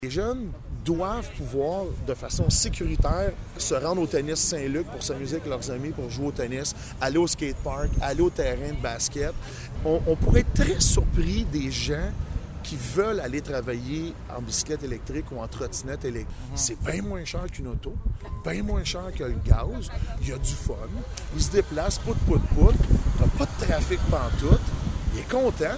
Le conseiller municipal responsable des activités sportives et de plein air, François Lemay, explique le phénomène par le fait qu’il s’agit d’une nécessité pour la population :